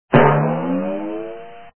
Descarga de Sonidos mp3 Gratis: rebotar.
bounce.mp3